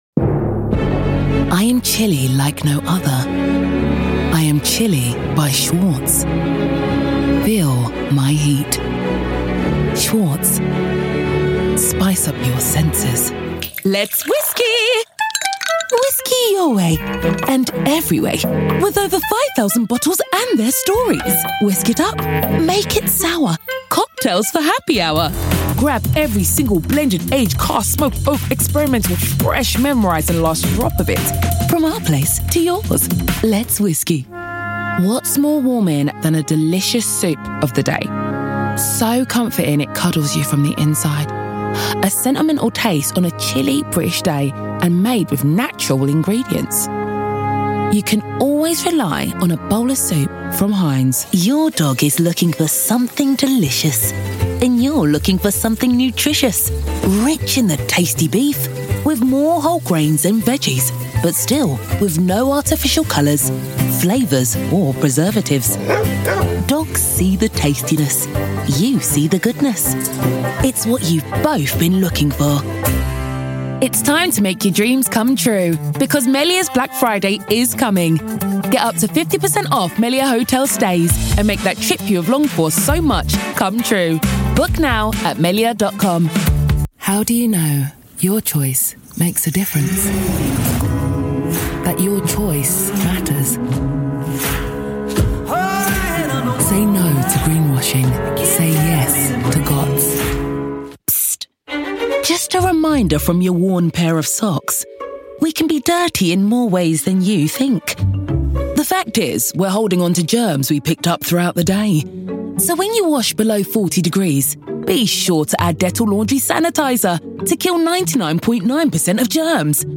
English (British)
Warm, Commercial, Deep, Friendly, Corporate
Telephony